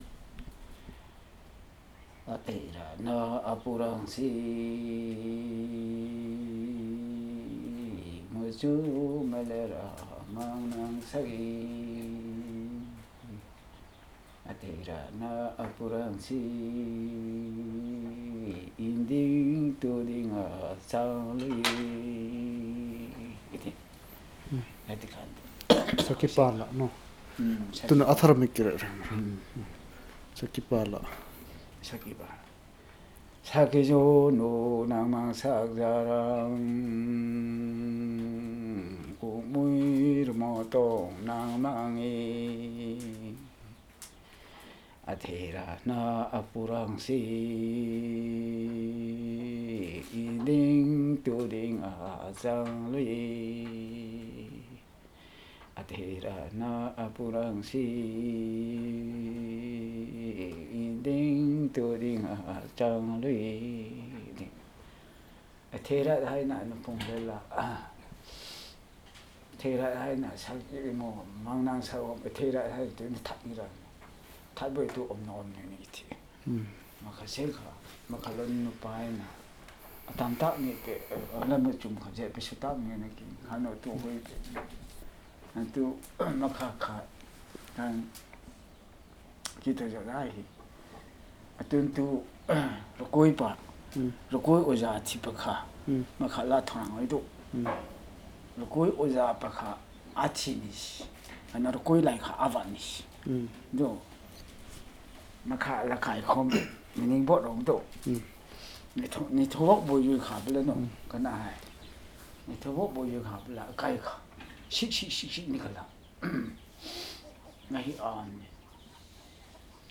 Performance of Folk song in Chiru